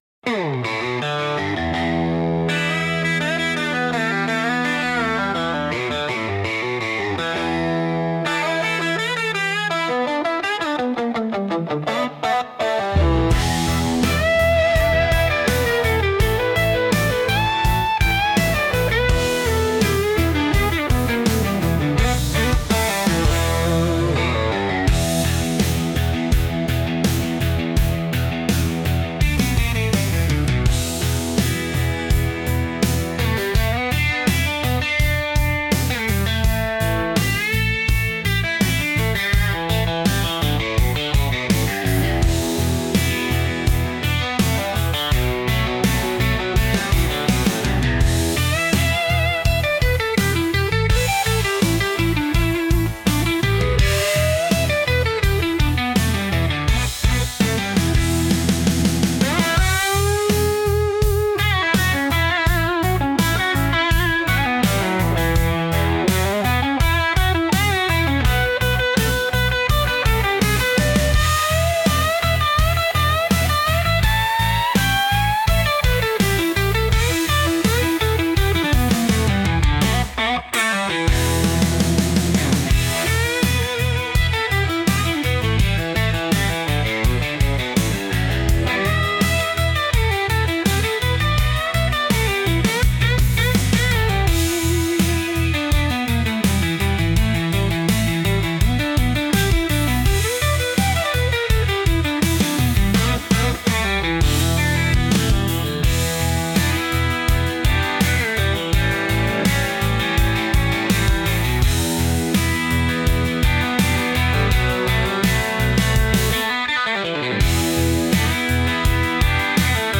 instrumental r.l.m. station music 4.20